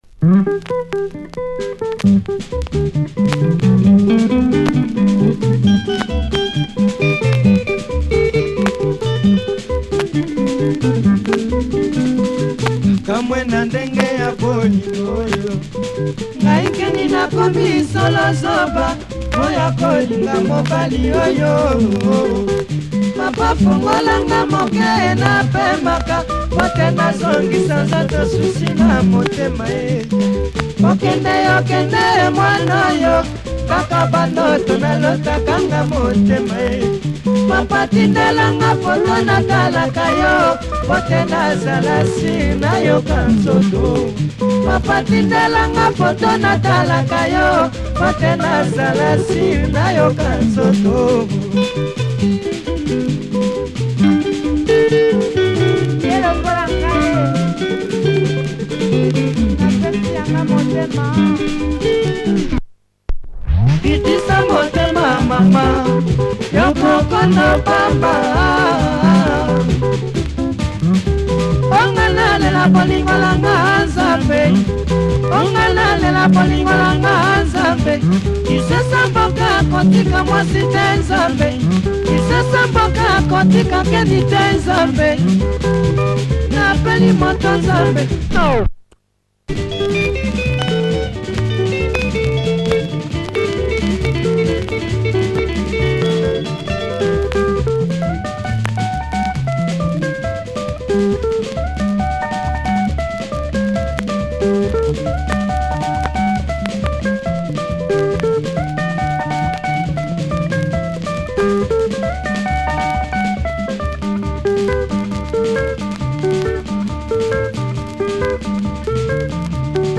Nice Lingala.